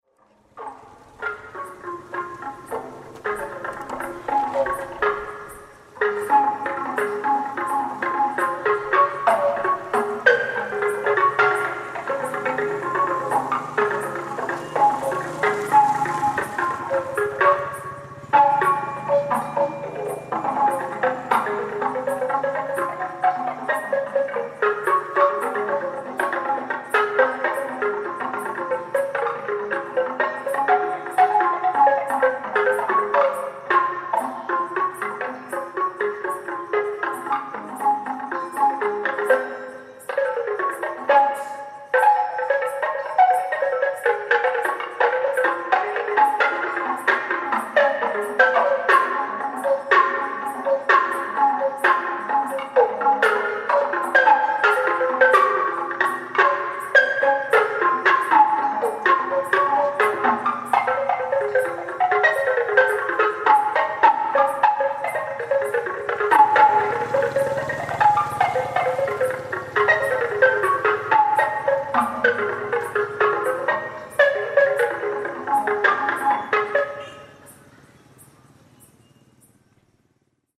Pattala, a Burmese xylophone
Pattala, a Burmese xylophone heard through a PA sound system, early evening.